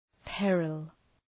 Προφορά
{‘perəl}